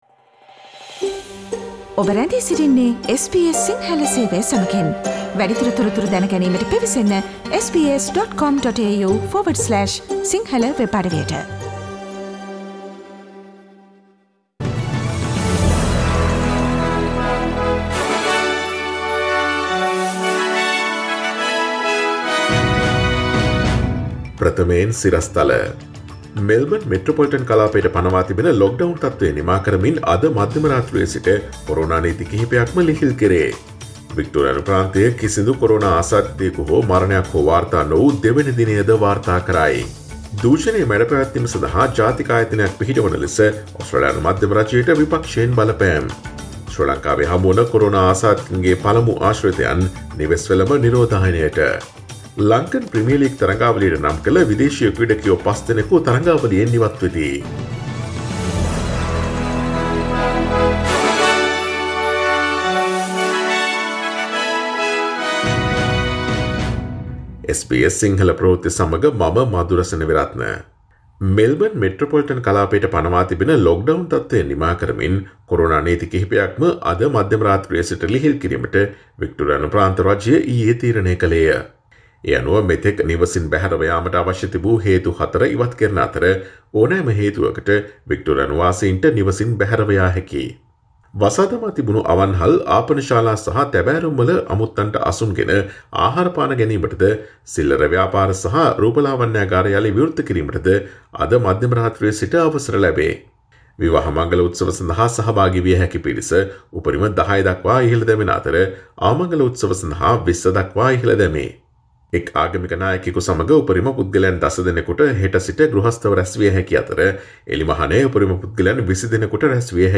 Daily News bulletin of SBS Sinhala Service: Tuesday 27 October 2020